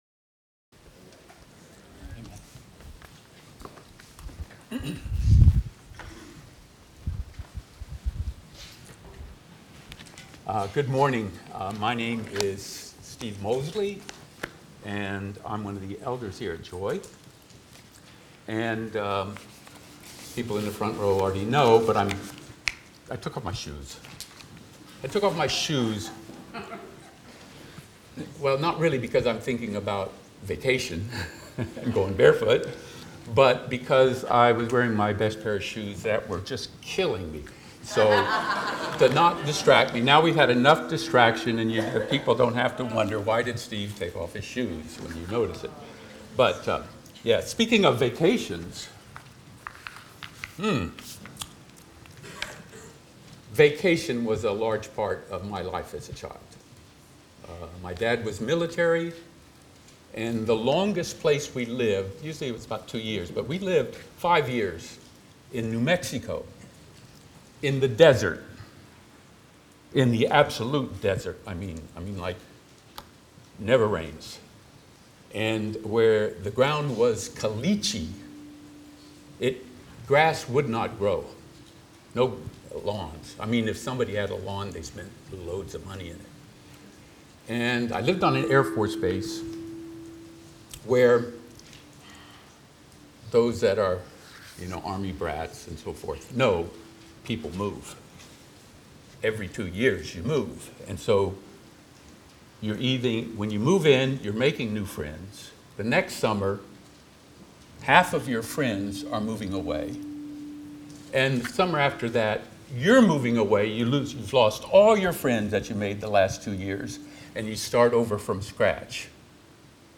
Title: Living by Faith in a World of Woe Text: Habakkuk 2:5–20 Originally delivered on 1 June 2025 at Stonington Baptist Church, Paxinos, PA